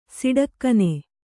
♪ siḍakkane